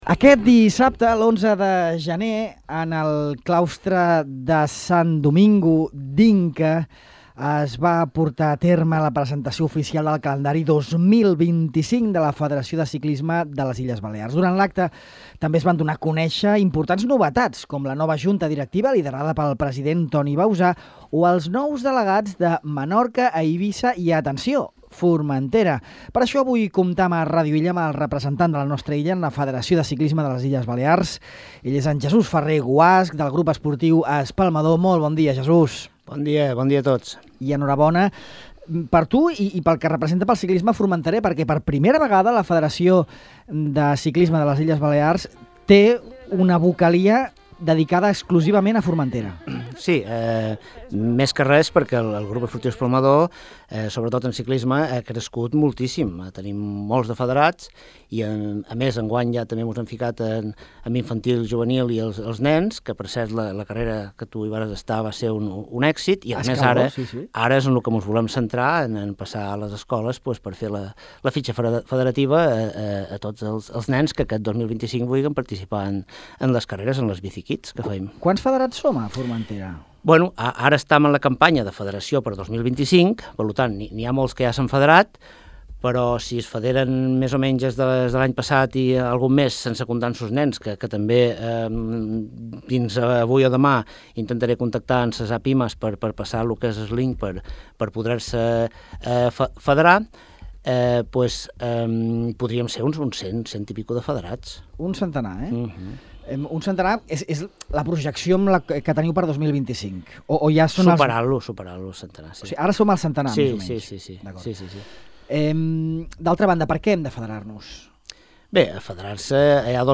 En aquesta entrevista a Ràdio Illa